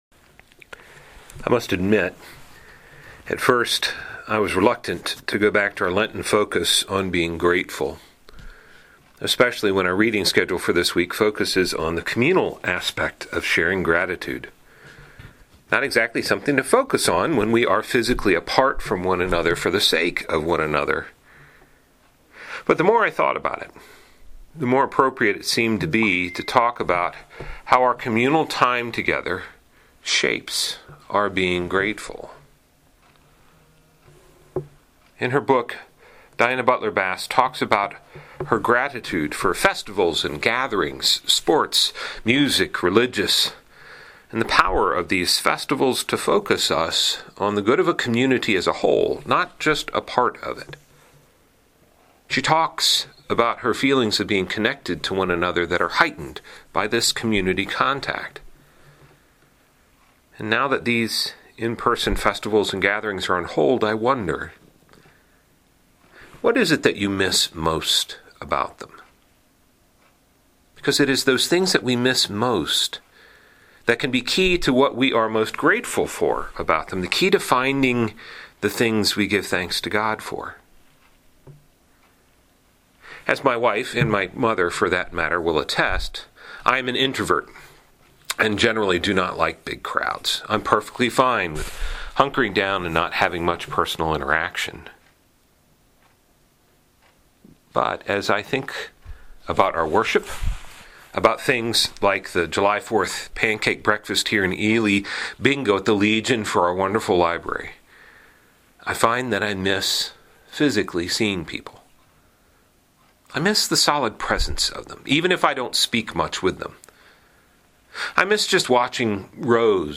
Midweek Lent Service March 25, 2020